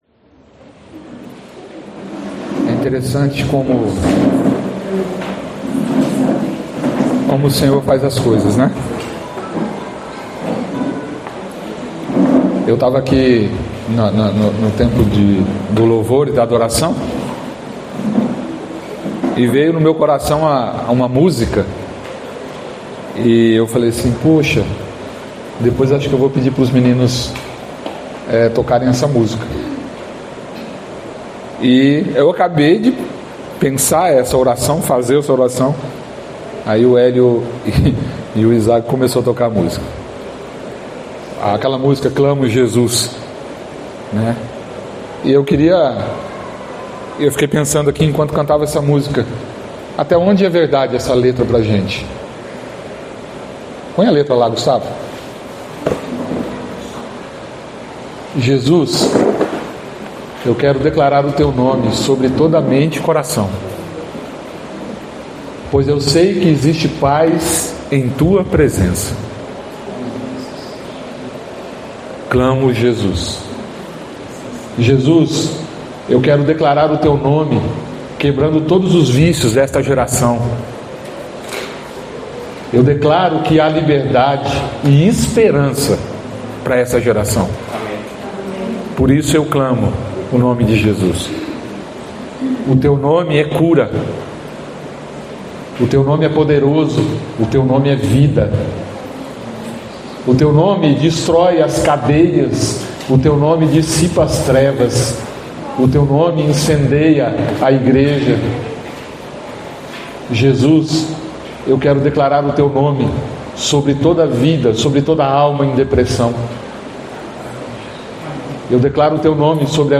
no culto do dia 01/03/2025
Palavras ministradas